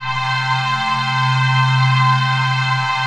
AMB SYN OC.wav